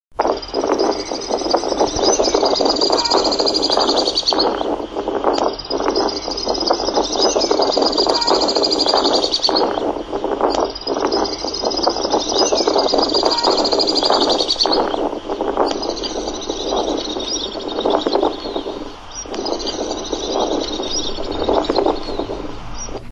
Tachymarptis melba
Molesta bastante el sonido del viento.
Nome em Inglês: Alpine Swift
Detalhada localização: Puente Romano
Condição: Selvagem